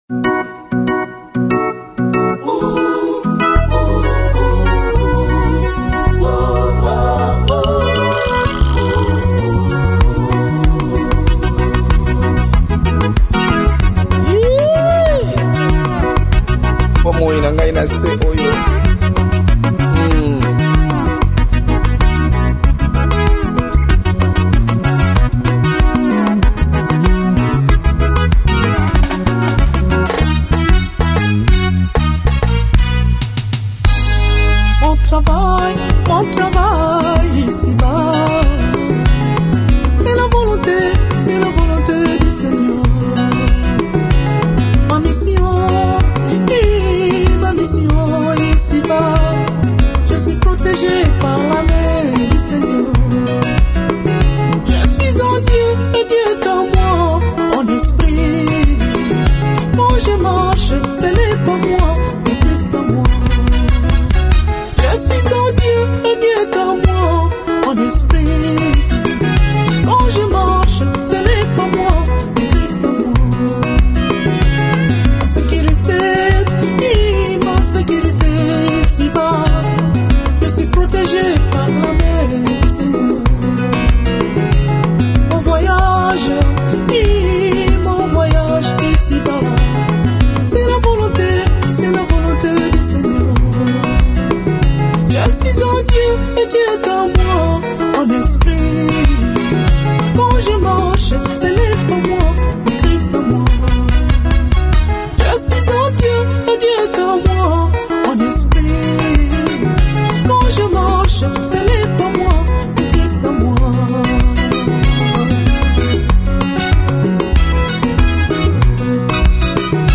Gospel 2008